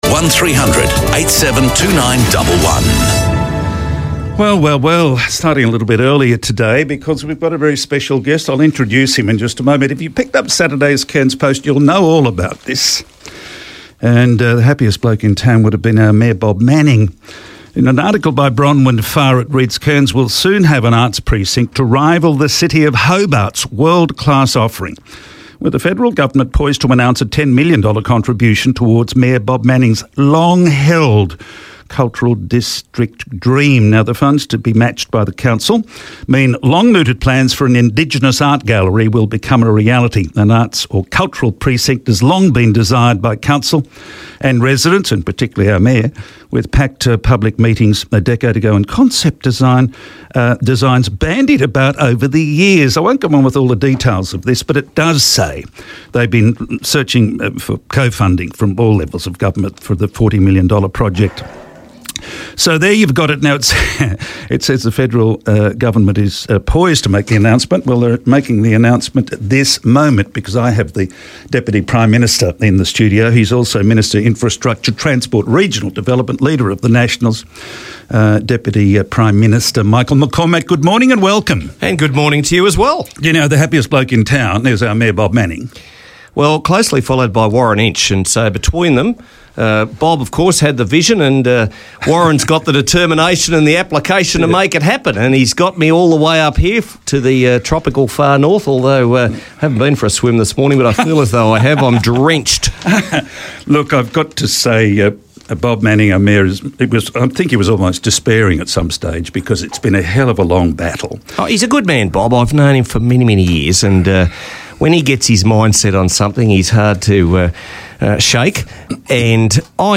Today in the studio I spoke to, Michael Mccormack, the Deputy Prime Minister and Minister for Infrastructure, Transport, and Regional Development, and leader of the Nationals, about the Australian Government's $10 million investment towards the Cairns Gallery Precinct.